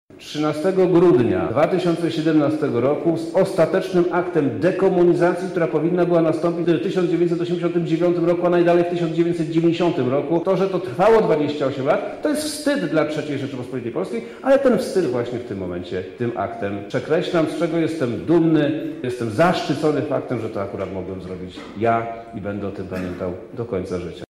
O szczegółach mówi Przemysław Czarnek, wojewoda Lubelski: